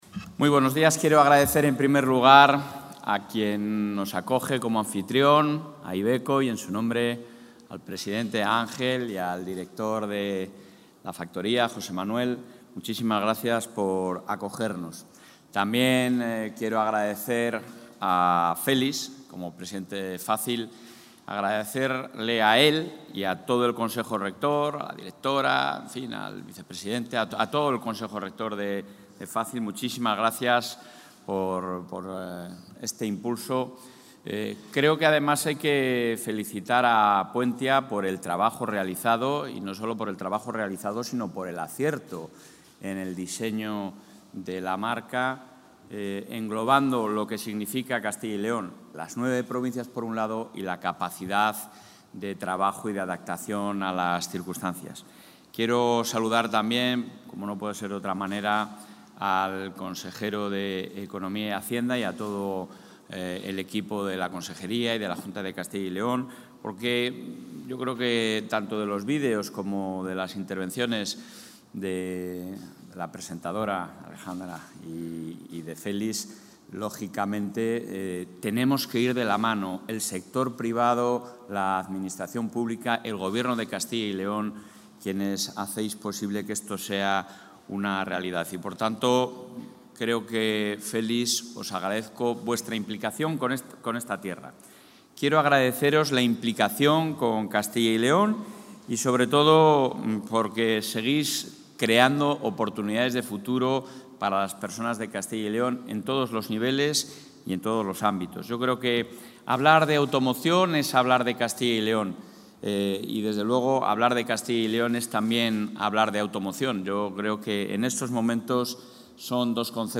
Intervención presidente.